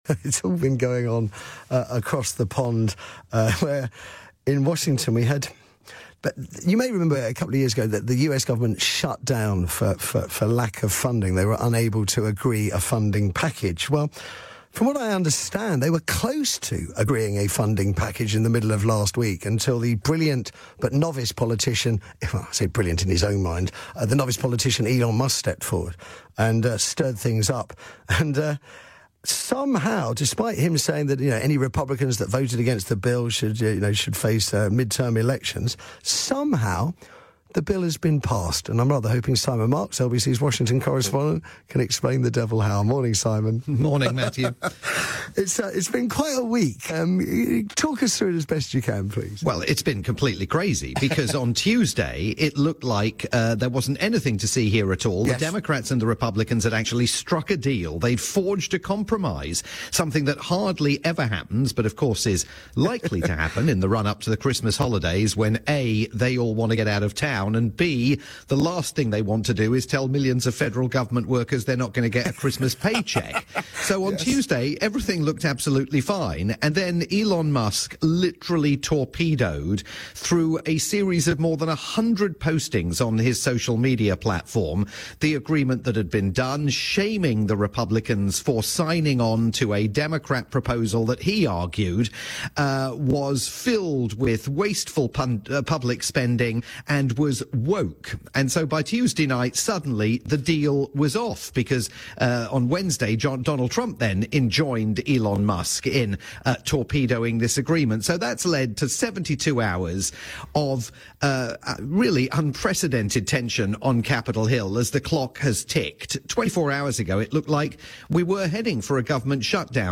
live report for Matthew Wright's Saturday morning breakfast programme on the UK's LBC.